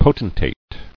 [po·ten·tate]